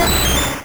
Cri d'Amonistar dans Pokémon Rouge et Bleu.